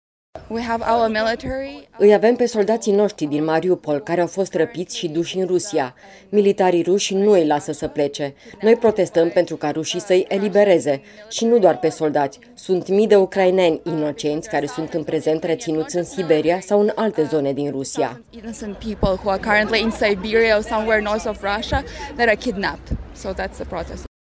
UCRAINEANCA.mp3